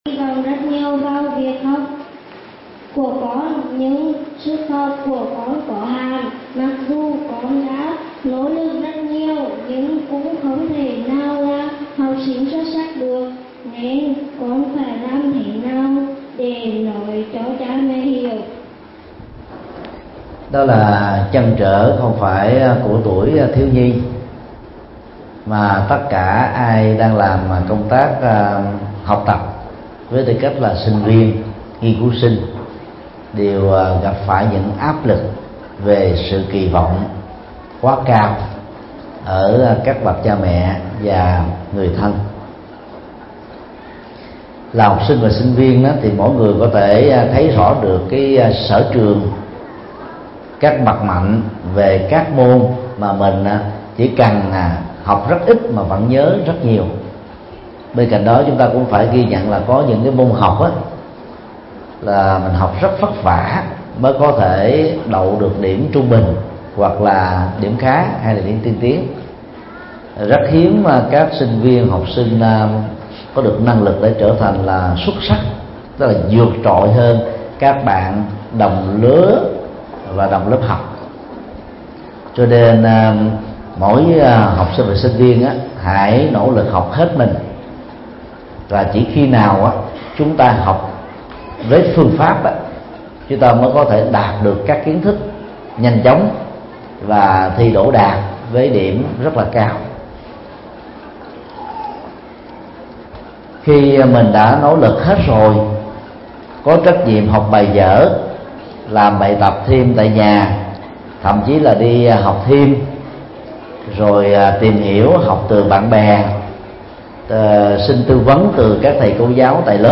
Vấn đáp: Phương pháp để cha mẹ thấu hiểu – Thích Nhật Từ